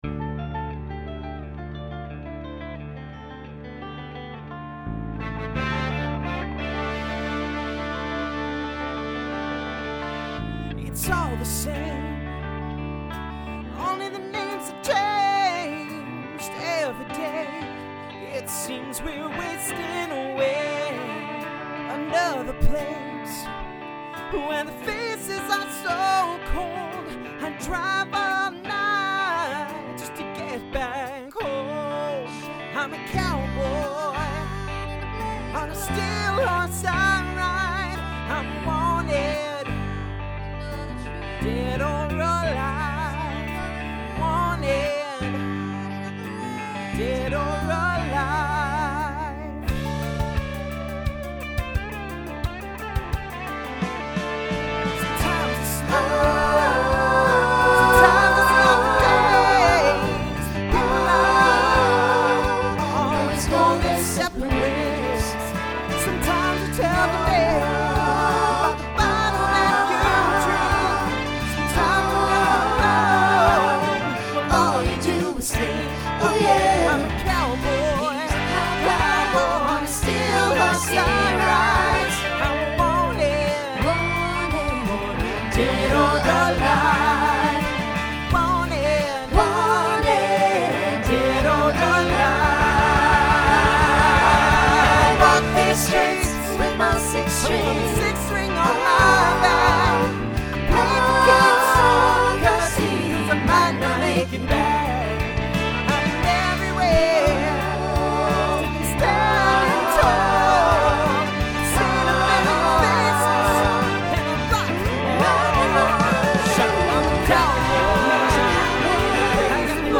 Mostly SATB with a chunk of SSA in the middle.
Genre Rock
Solo Feature Voicing Mixed